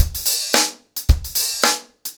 DaveAndMe-110BPM.7.wav